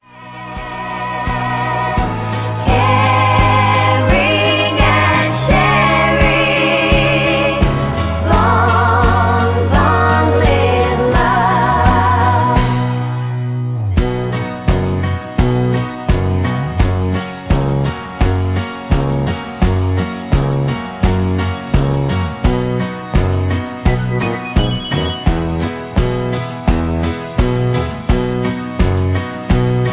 Karaoke MP3 Backing Tracks
mp3 backing tracks